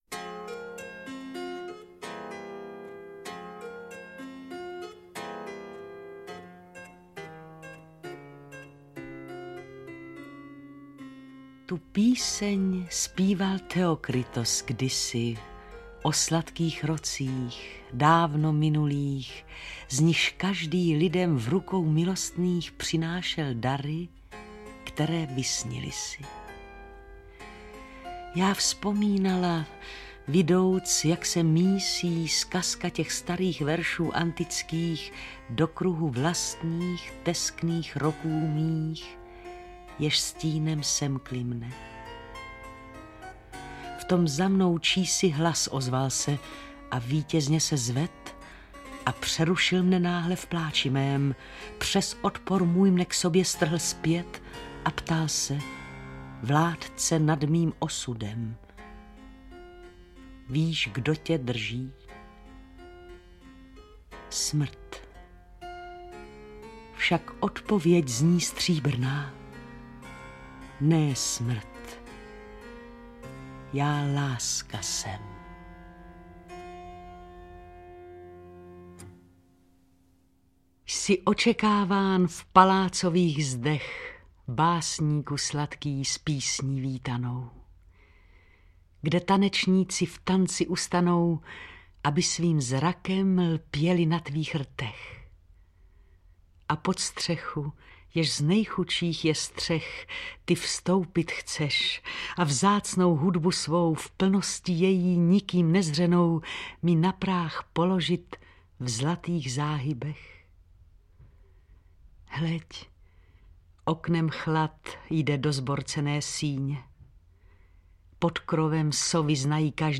Interpret:  Jiřina Petrovická
AudioKniha ke stažení, 1 x mp3, délka 46 min., velikost 42,5 MB, česky